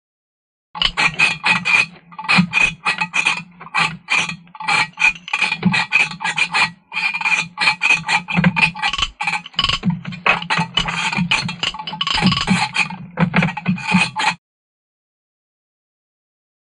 Loom; Hand Loom Being Set Up. Mainly Clanks And Bangs.